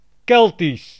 Ääntäminen
Synonyymit celtique Ääntäminen France: IPA: /sɛlt/ Haettu sana löytyi näillä lähdekielillä: ranska Käännös Ääninäyte Adjektiivit 1.